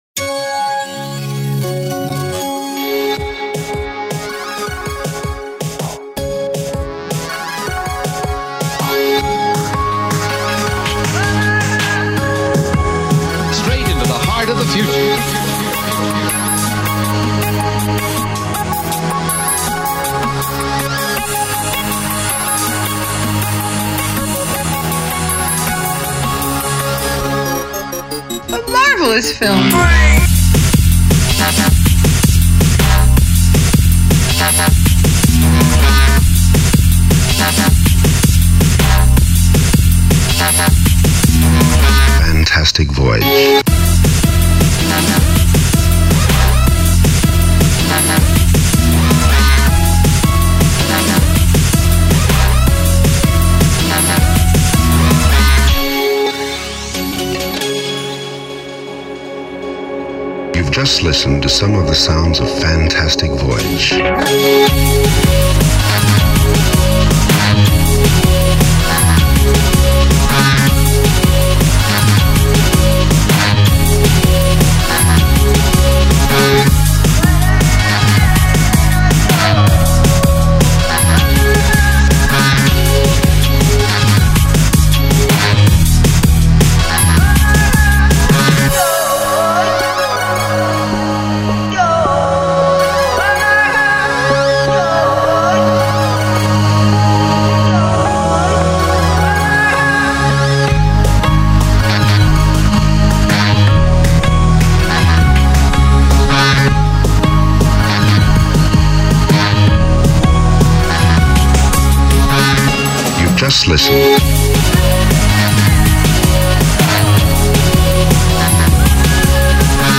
трек на конкурс Intel с использованием сємпла фирменного стиля